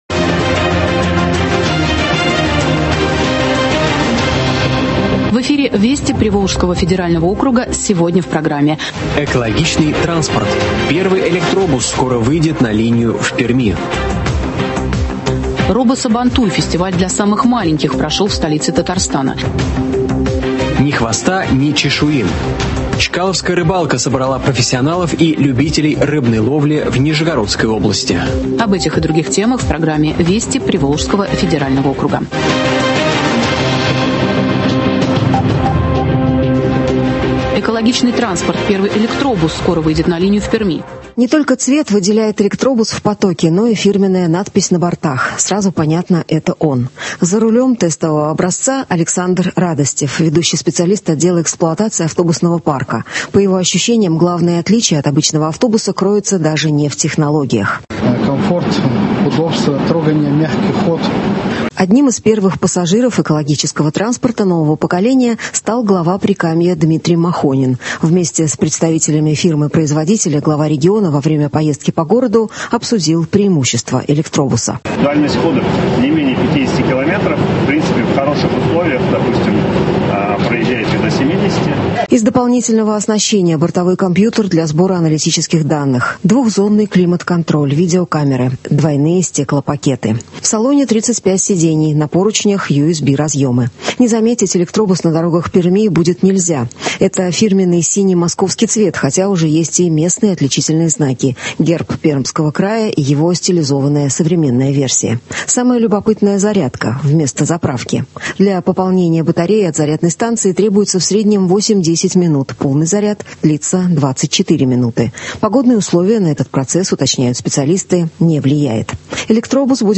Радиообзор событий недели в регионах округа.